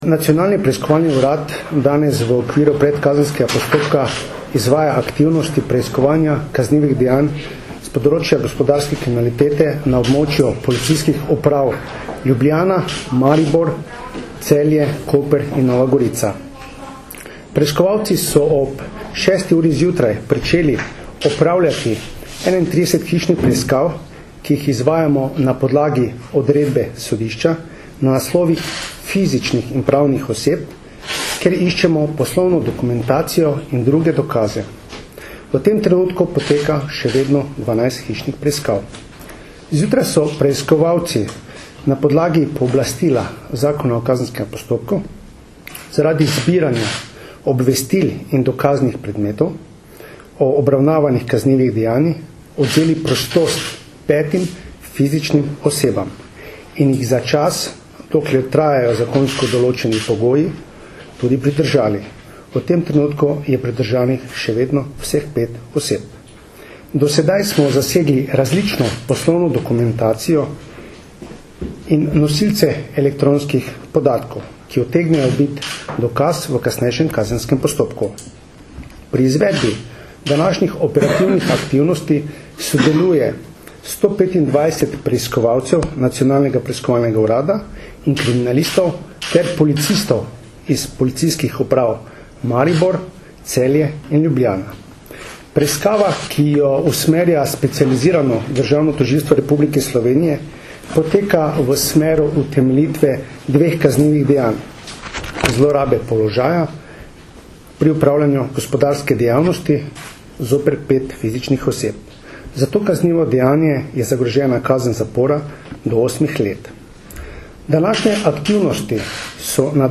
Zvočni posnetek izjave direktorja NPU Darka Majheniča (mp3)